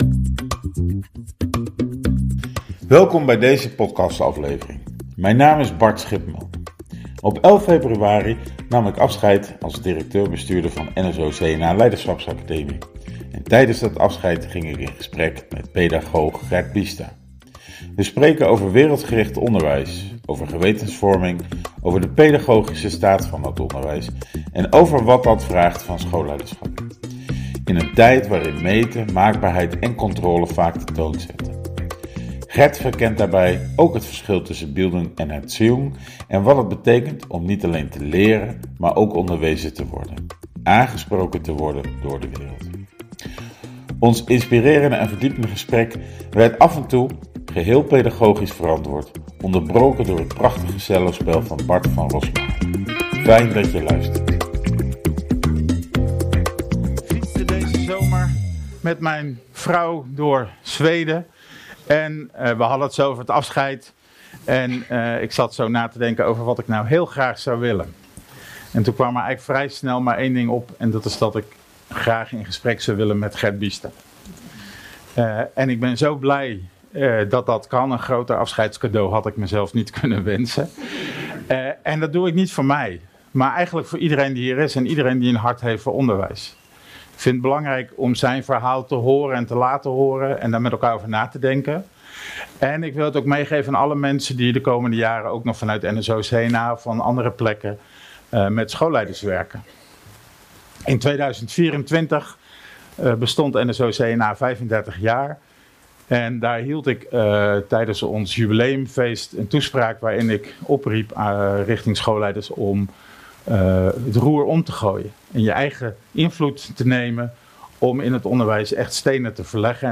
In gesprek met Gert Biesta over wereldgericht onderwijs